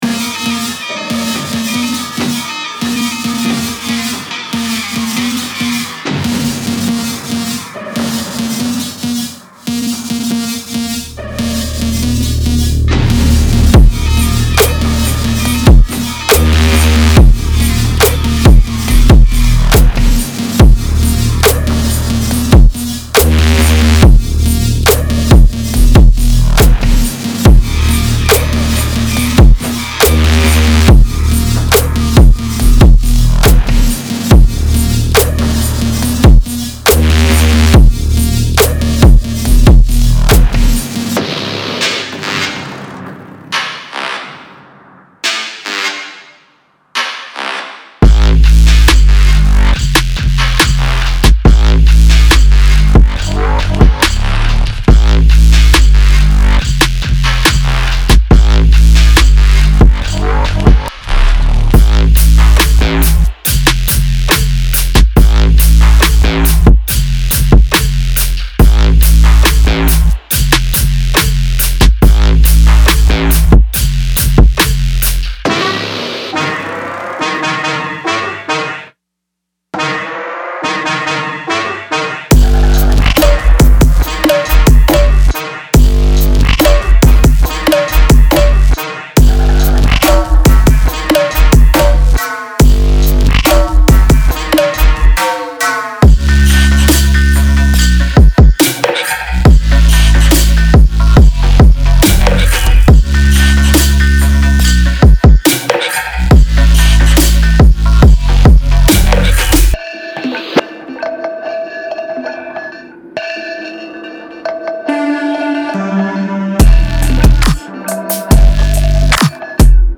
DubstepHip HopTrap
挤满了颤抖的低音，混乱的旋律和强劲的鼓声-可以在地下节拍和低音音乐场景的边缘了解各种声音。